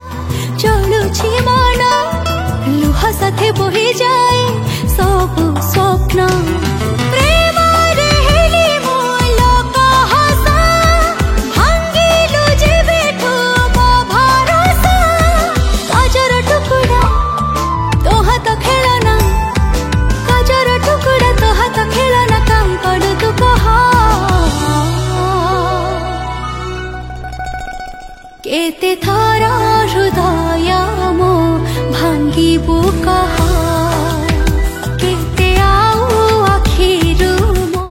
sad song